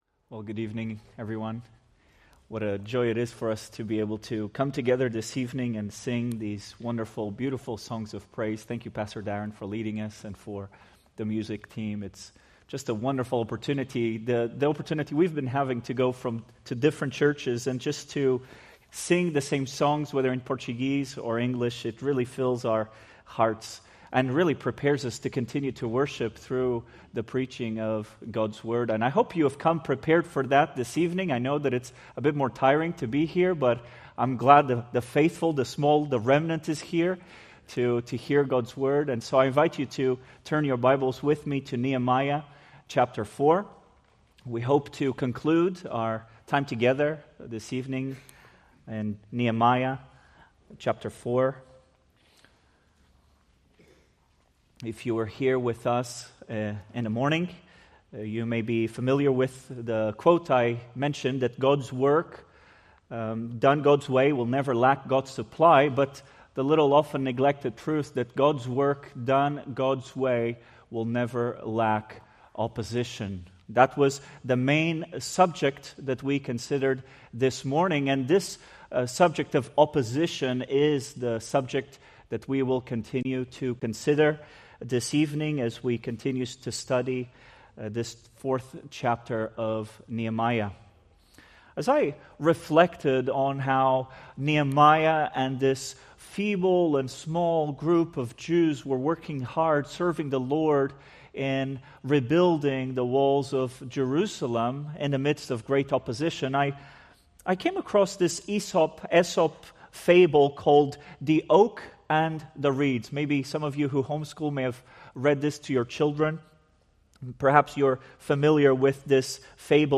Preached March 1, 2026 from Nehemiah 4:7-14